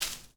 SFX_paso2.wav